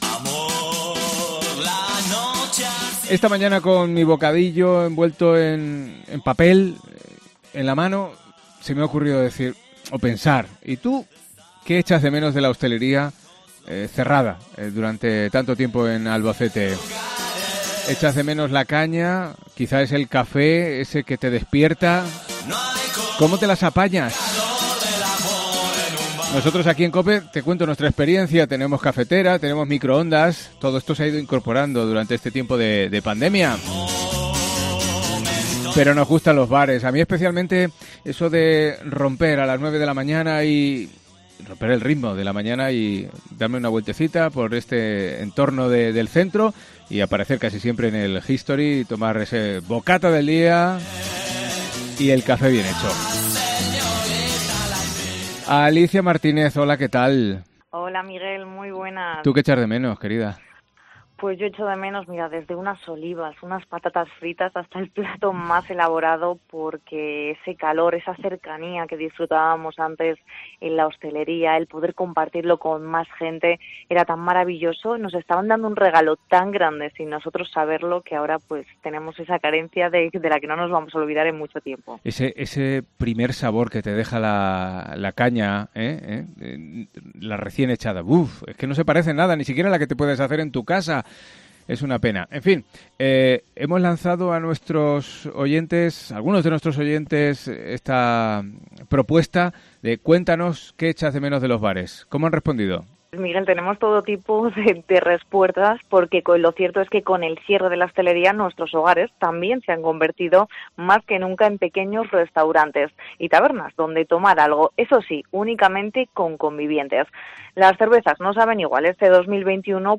REPORTAJE COPE
Hablan algunos oyentes que nos cuentan lo que echan en falta con el cierre de bares y restaurantes